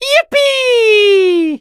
18 KB {{aboutfile |1=The sound Mario makes when he yippees.
Mario_(Yippee)_-_Super_Mario_Party_Jamboree.ogg